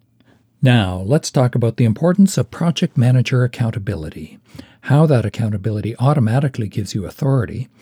• Equalization with low rolloff for speech
• RMS normalize -18 dB
• Soft limit -3 dB
[For some reason, (mic rattle ?), “accountability” sounds like “accountRability”].